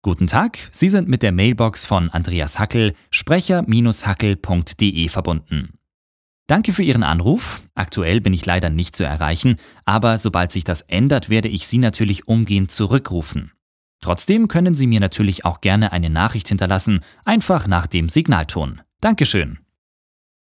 Sprecher Telefonansage | HiProCall